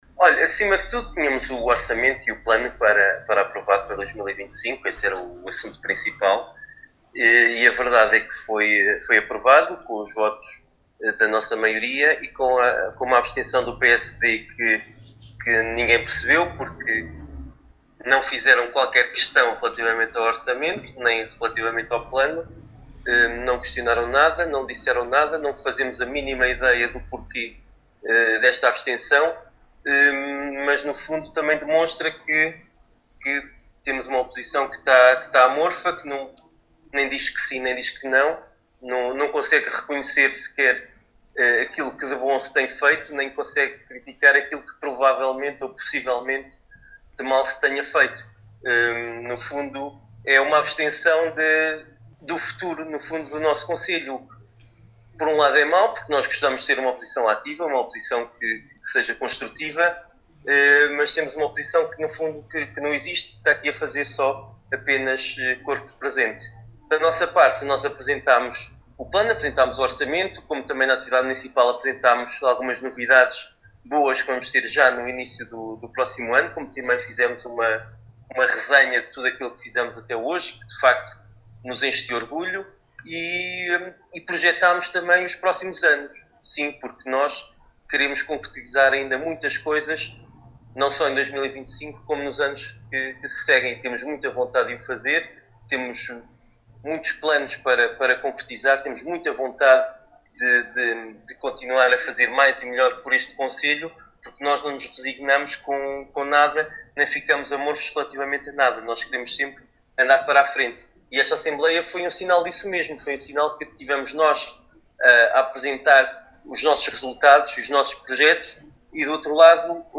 Paulo Marques, Presidente do Município de Vila Nova de Paiva, em declarações à Alive FM, disse que o principal assunto em ordem dia era sem dúvida o Orçamento e o Plano Orçamental Plurianual e Grandes Opções do Plano (GOP) para 2025, um ponto que foi aprovado com a abstenção do PSD, “o que demonstra uma oposição que está amorfa…”.